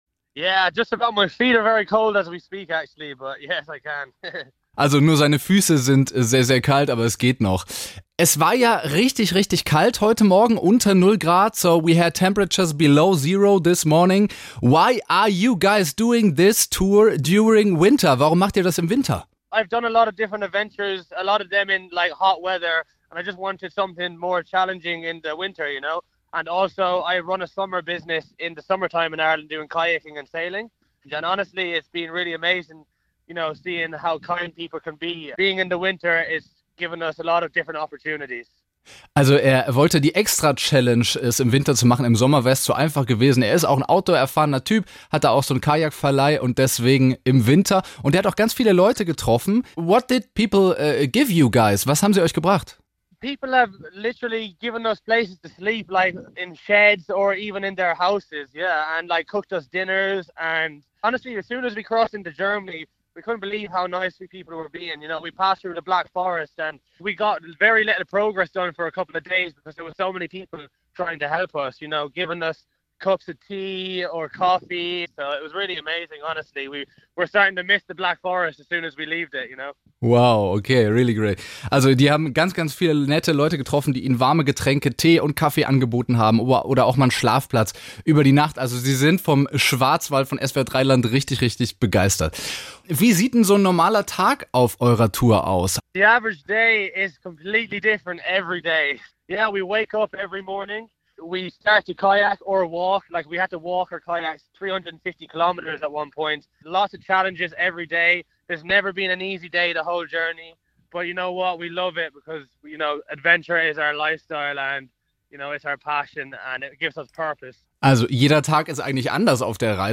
Nachrichten SWR3 Interview